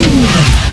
Drop.wav